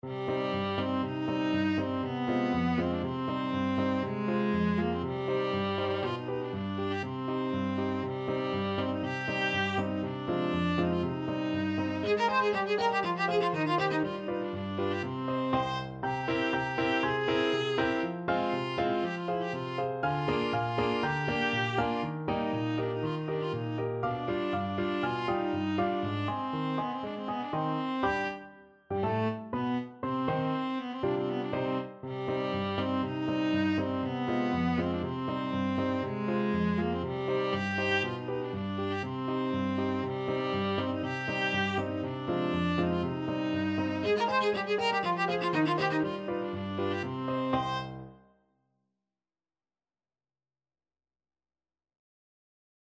Viola version
ViolaPiano
Allegro (View more music marked Allegro)
Classical (View more Classical Viola Music)